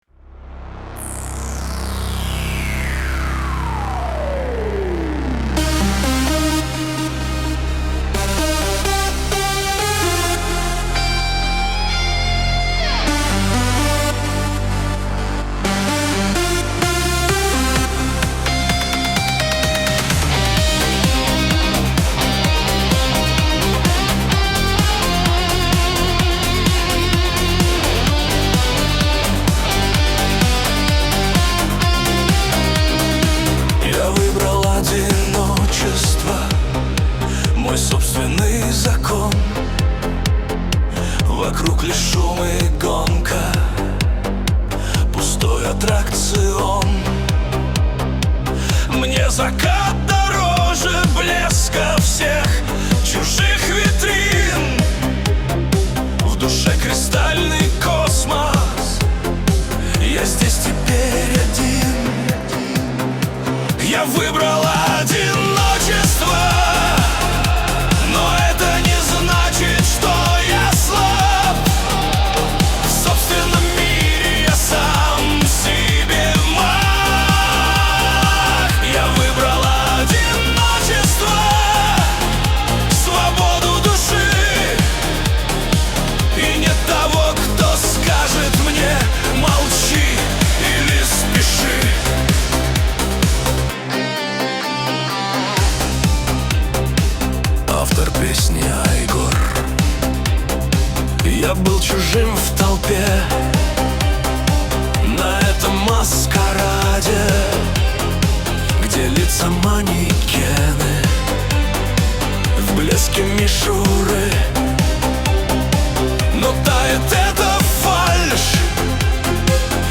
Качество: 252 kbps, stereo